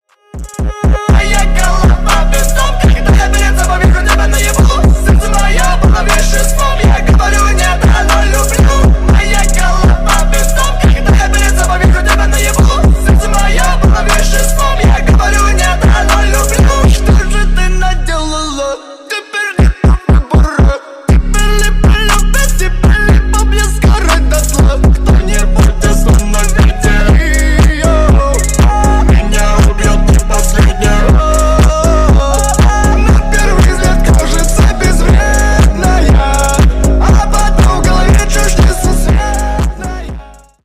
Рэп и Хип Хоп
громкие